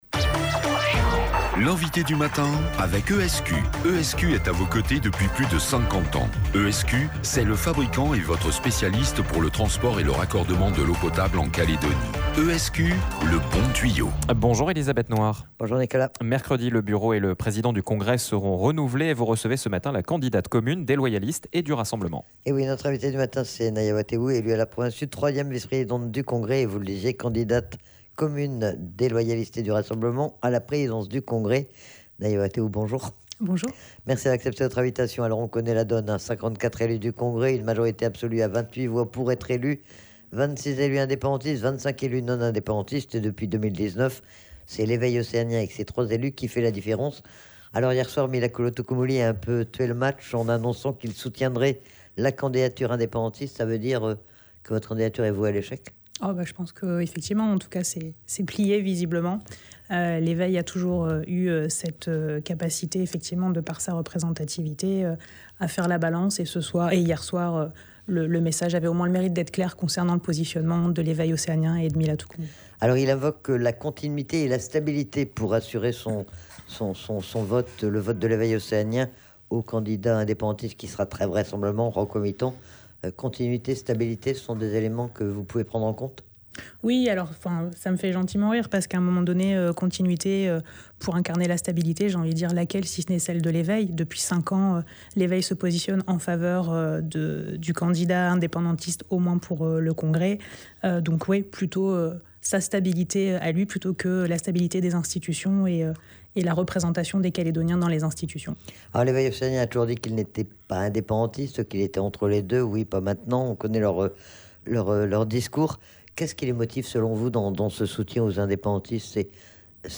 L'INVITEE DU MATIN : NAIA WATEOU
Naïa Watéou, la candidate commune des Loyalistes et du Rassemblement, à la présidence du Congrès était notre invitée du matin. Une candidature mise à rude épreuve puisque la veille, Milakulo Tukumuli, le président de l'Eveil océanien, a annoncé que son parti voterait pour les indépendantistes, ce qui pourrait faire pencher la balance.